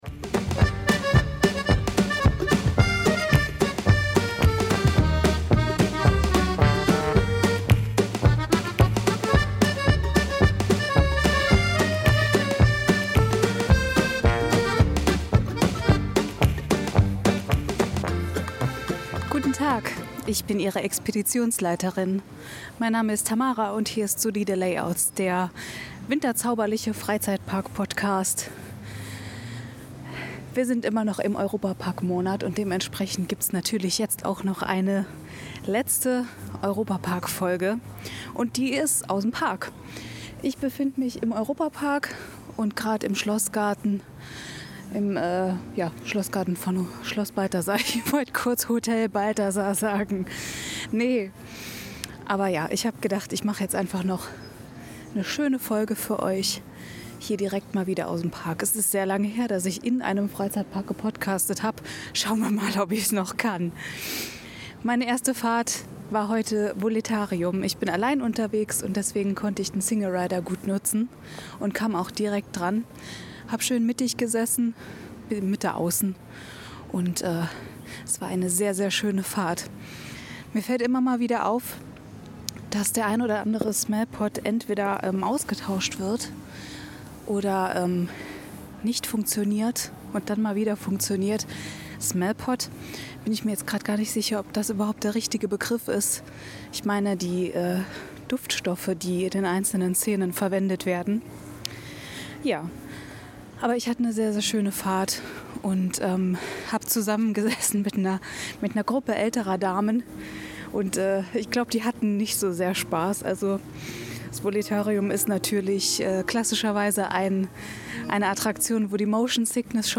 Eine kleine on-site Bonusfolge und Kritik in Sachen Tiere in Freizeitparks.